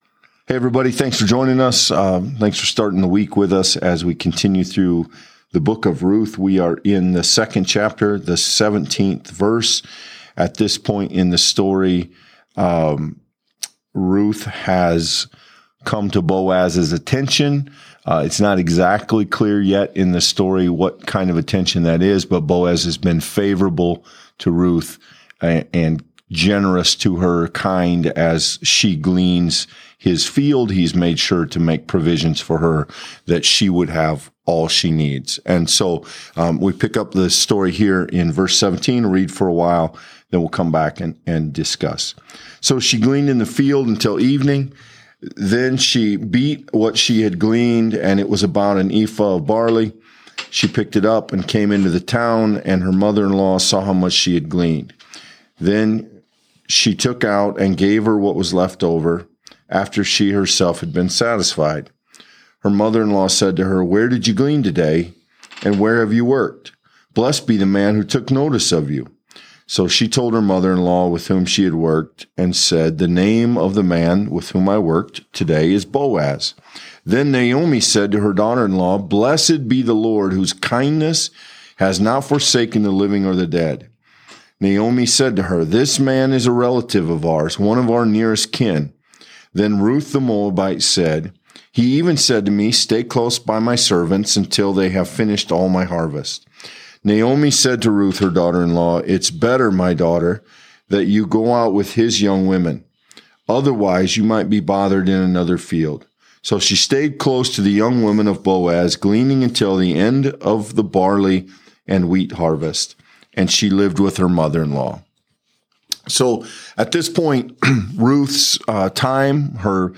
In this engaging Bible study